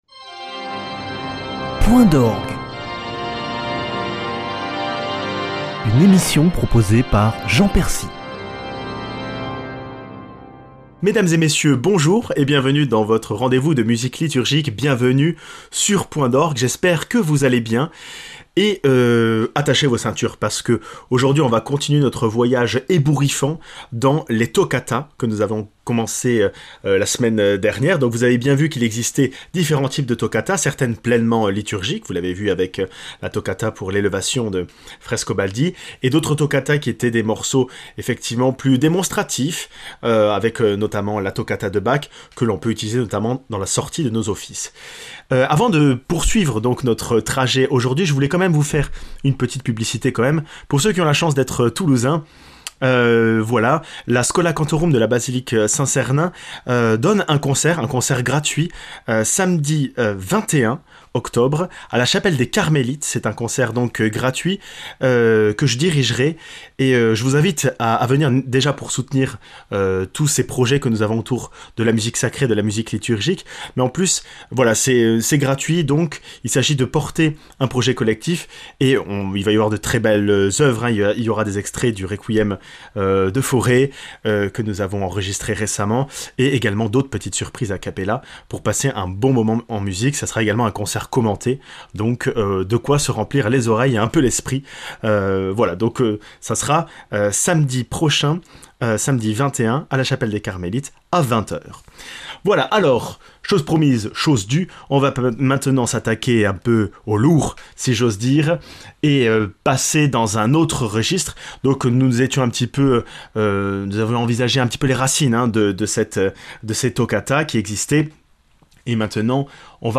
Découvrons cette année deux des grands maitres de l'orgue : Tournemire et Widor avec des grands joyaux de la musique.